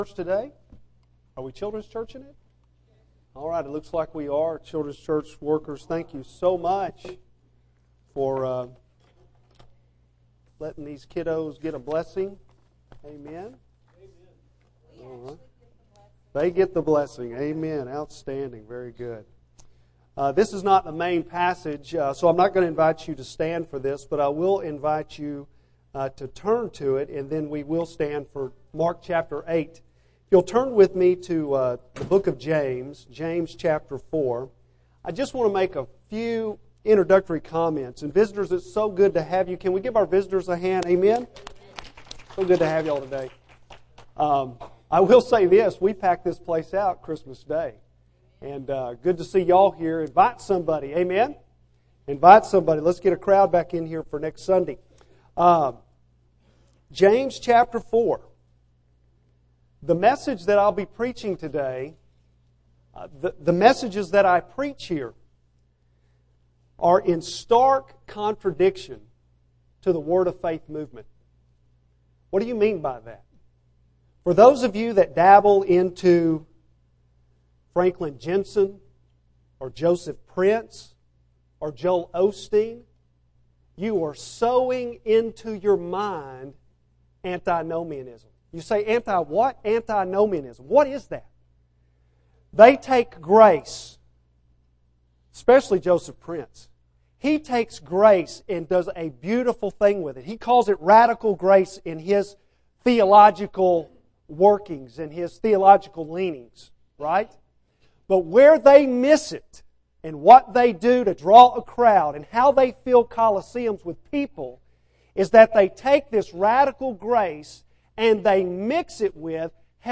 Jan 01 AM - New Hope Baptist Church